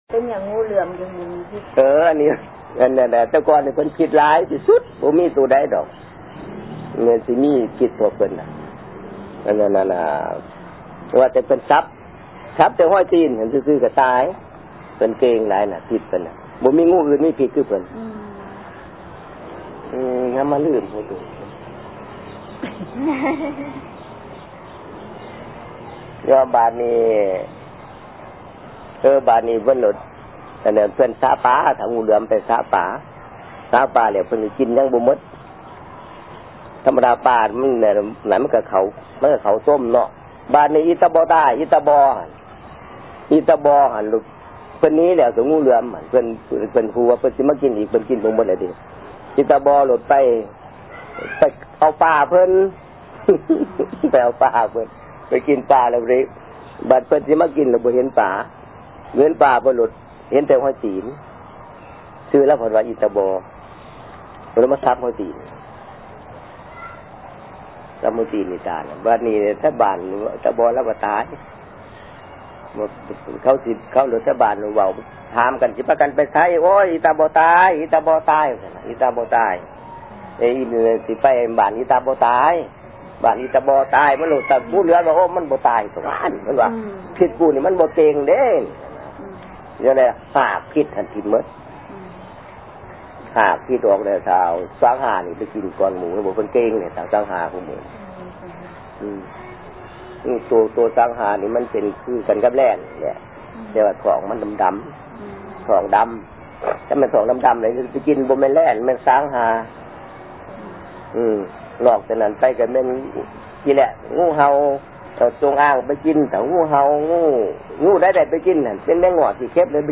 Folktales:
(Vientiane, Laos)